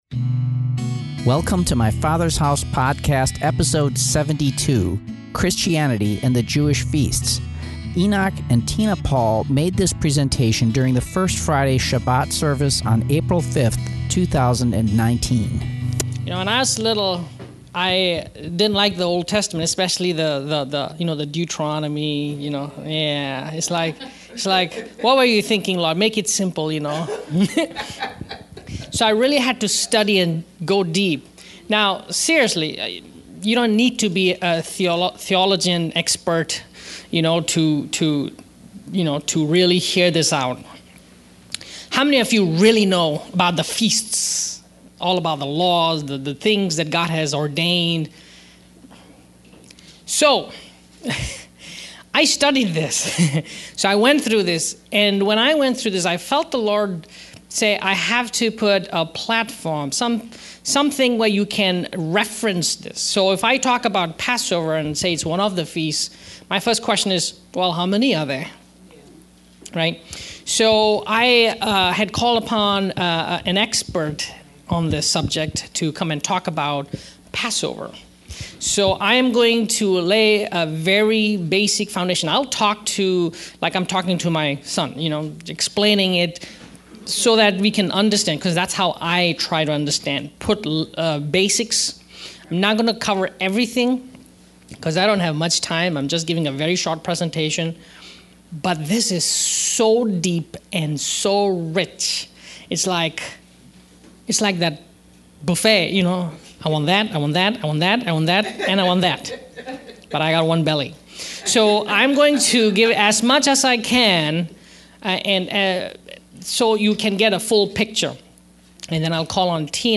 Our First Friday Shabat meetings are about helping Christians connect with the Jewish roots of thier faith. The message was about the Feasts the Lord ordained for his people, as celebrations of his creation.